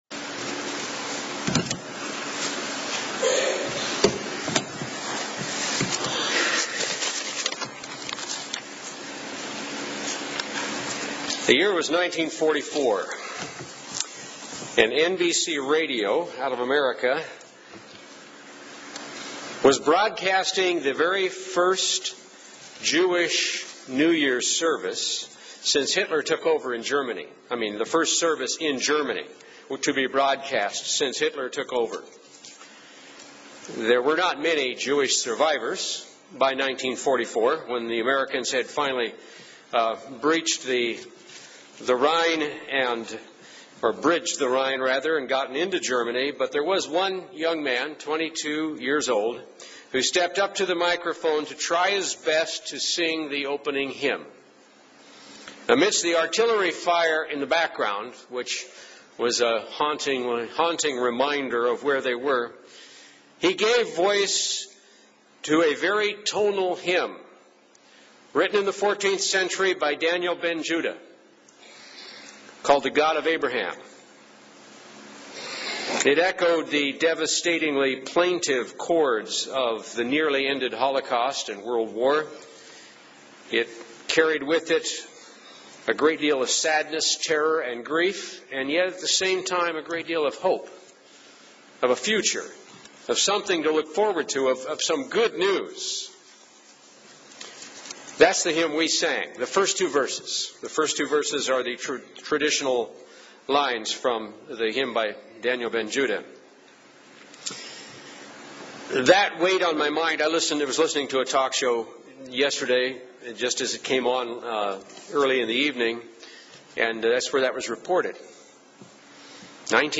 Print 3 points from the hymn "The God of Abraham" on the Feast of Trumpets UCG Sermon Studying the bible?